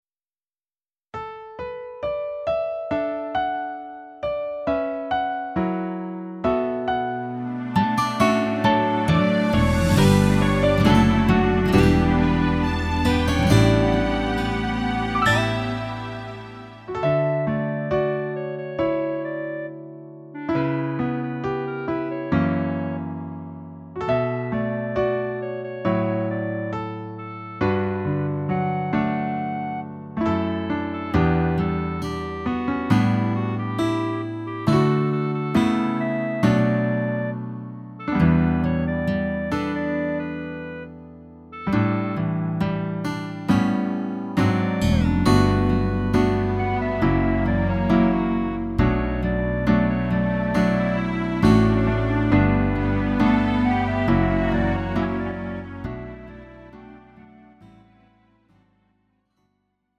음정 -1키 4:37
장르 가요 구분 Lite MR